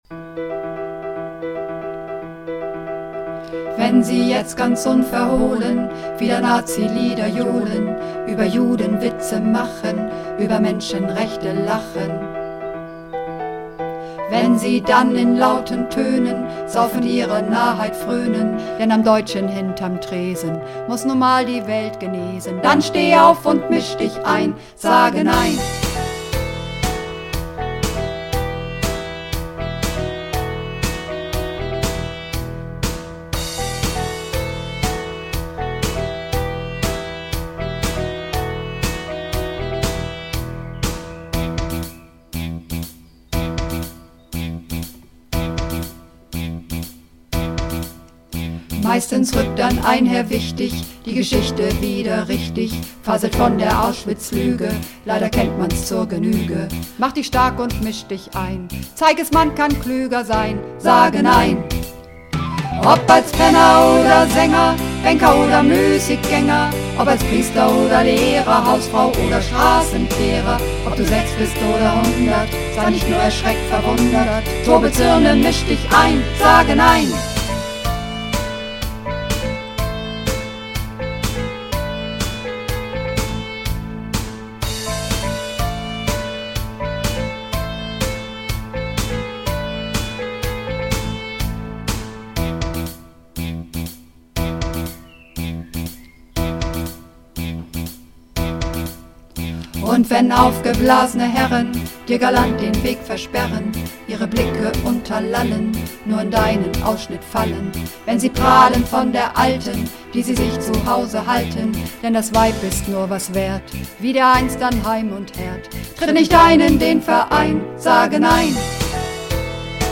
Übungsaufnahmen
Runterladen (Mit rechter Maustaste anklicken, Menübefehl auswählen)   Sage Nein (Mehrstimmig)
Sage_Nein__4_Mehrstimmig.mp3